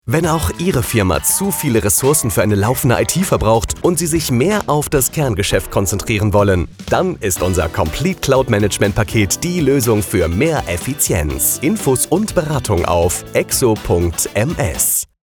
Bekannt aus dem Radio
Funkspot_ExoHost-GmbH-15-Sek.mp3